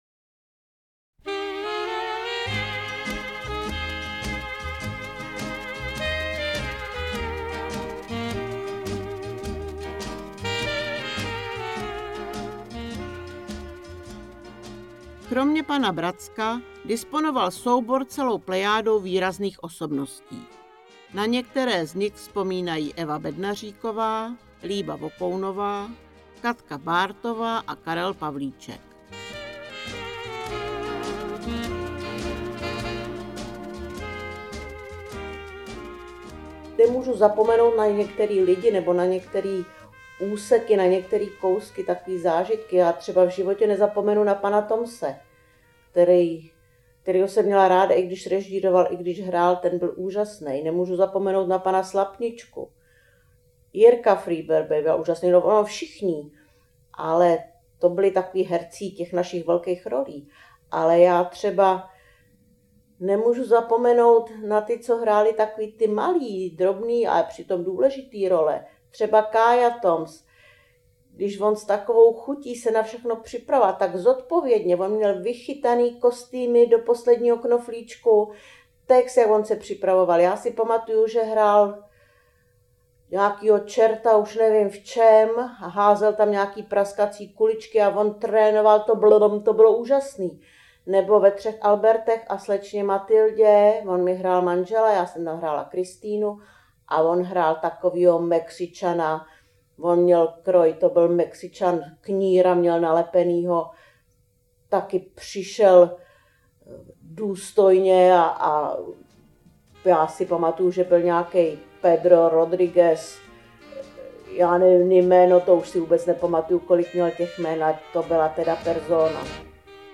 Komentované listinné a obrazové dokumenty, vzpomínky křivoklátských ochotníků.